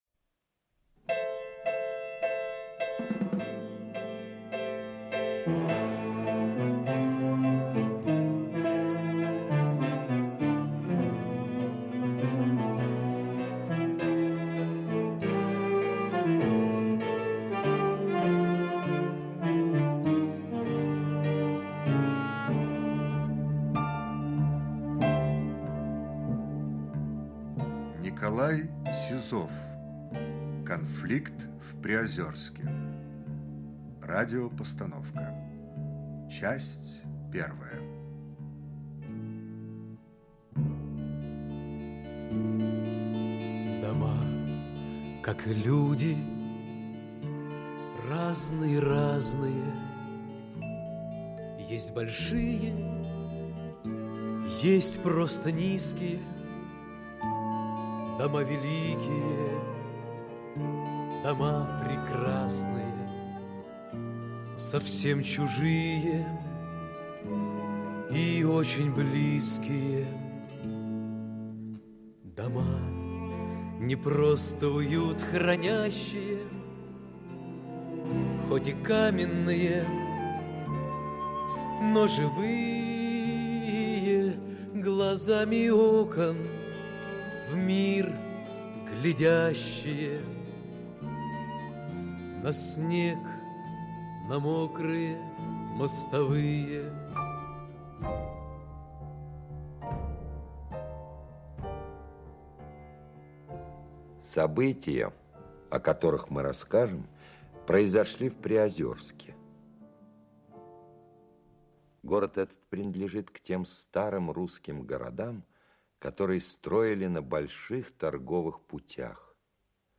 Аудиокнига Конфликт в Приозерске. Часть 1 | Библиотека аудиокниг
Часть 1 Автор Николай Трофимович Сизов Читает аудиокнигу Олег Табаков.